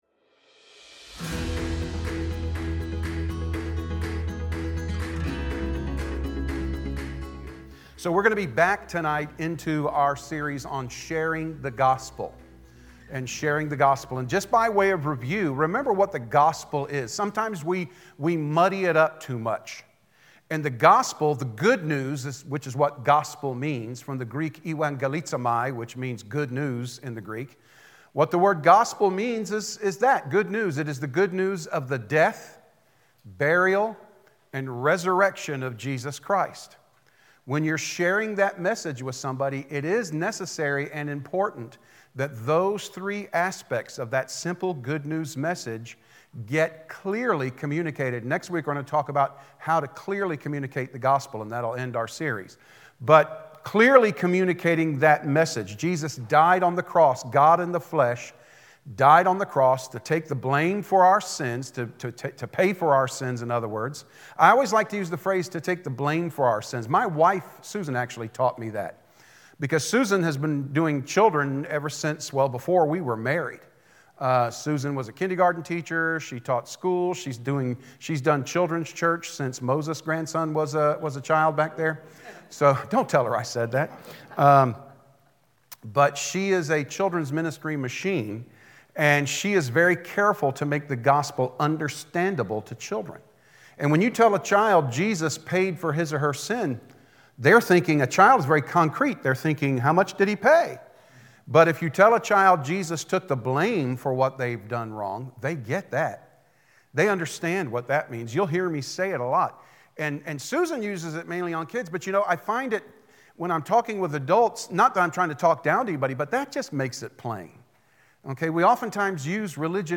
Wednesday Bible Study Sharing The Gospel Get Ready, Be Ready!